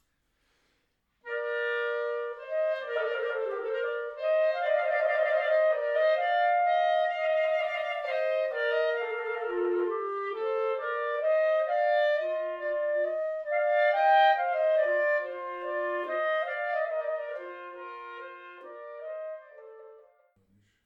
Besetzung: 2 Klarinetten